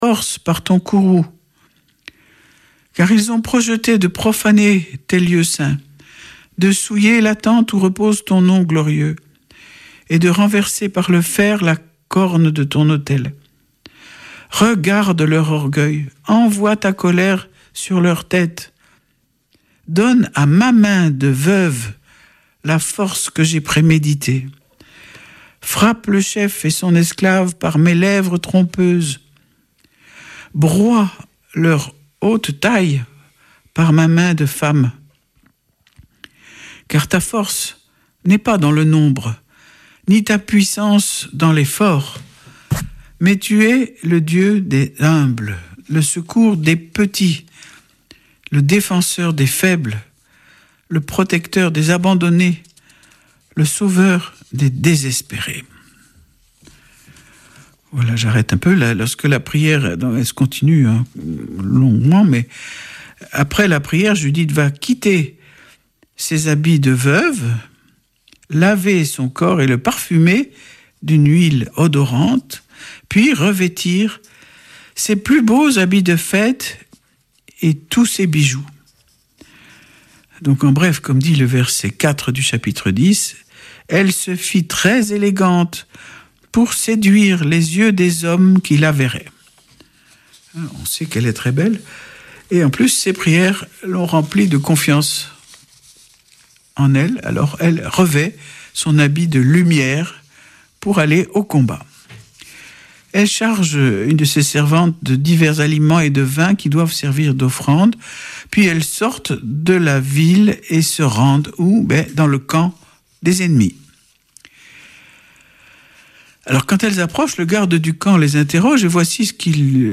Vêpres de Saint Sernin du 24 août